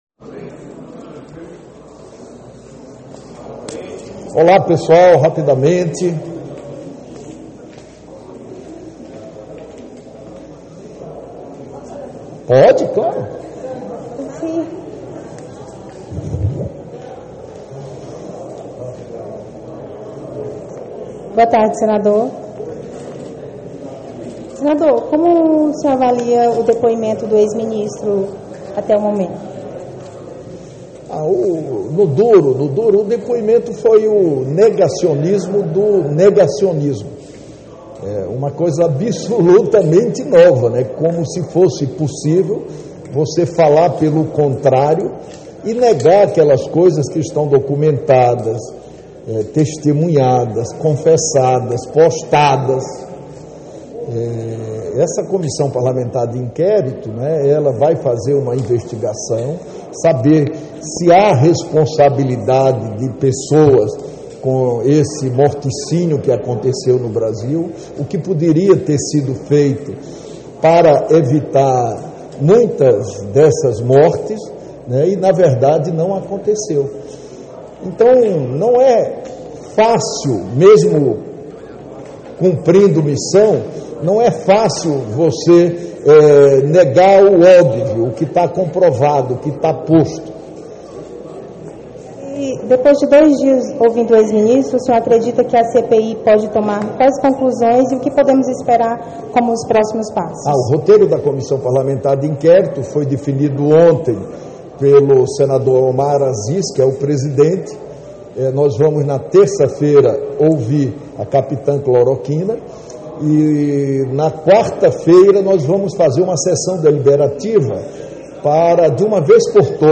Entrevista Renan Calheiros, relator da CPI da Pandemia
Entrevista Coletiva